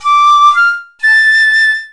flute.mp3